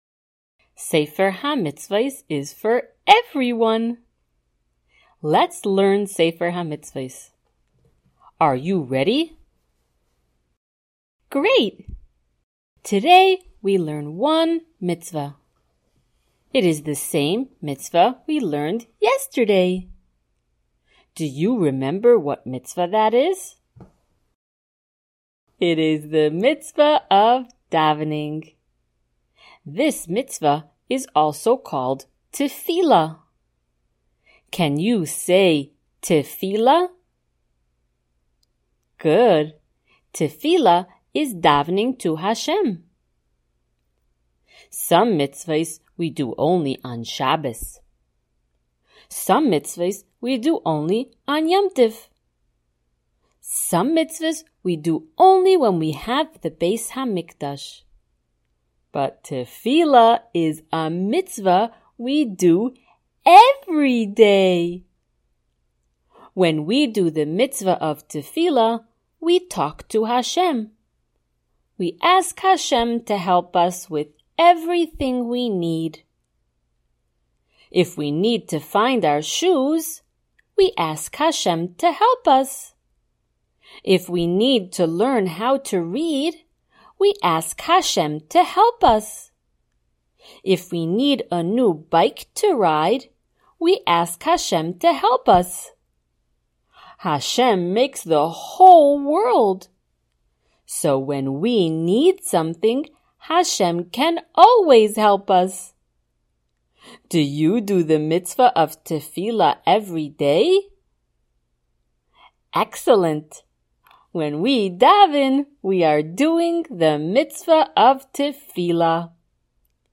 SmallChildren_Shiur024.mp3